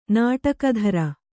♪ nāṭakadhara